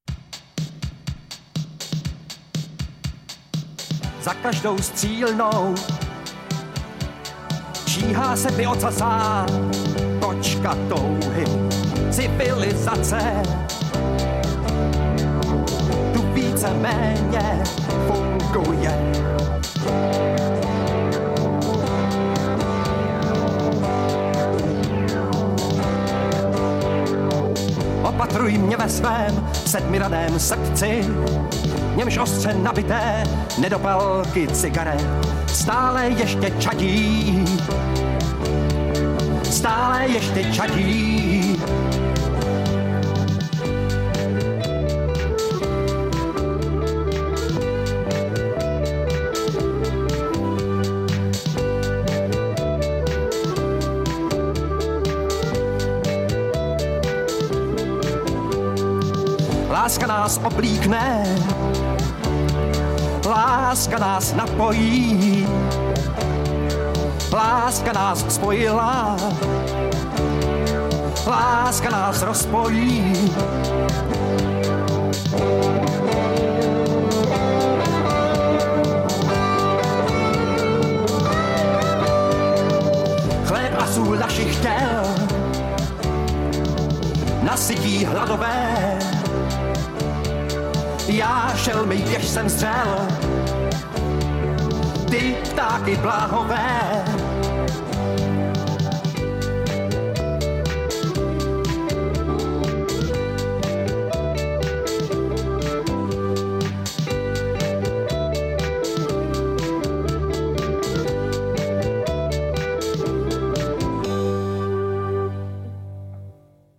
Audiokniha
Ale má něco do sebe: jde o totální výpověď, kde hudba není doplňkem, ozdobou, náhodností, ale ani funkční možností je víc: nutností, tvoří se slovem dvojhlas, jednotu, společný svět....